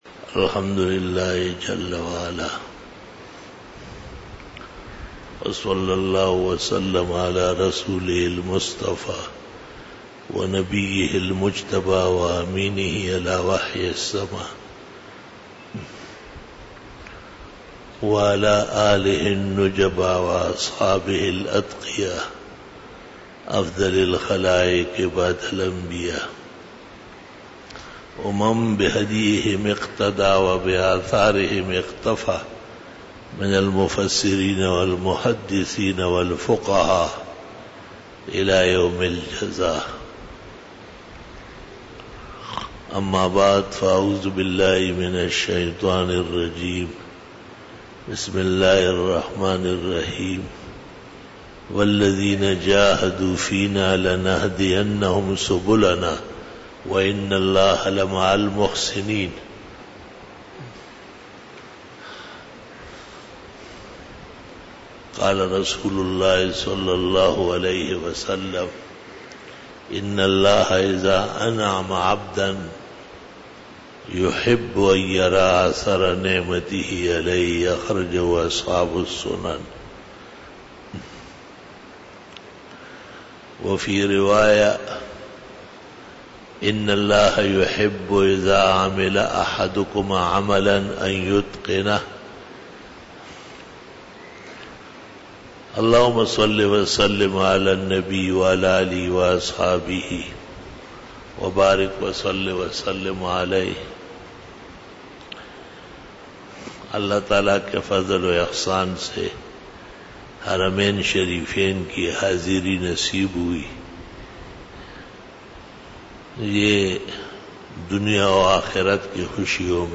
18 BAYAN E JUMA TUL MUBARAK (4 May 2018) (17 Shaban 1439H)
Khitab-e-Jummah 2018